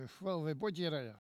Langue Maraîchin
locutions vernaculaires
Catégorie Locution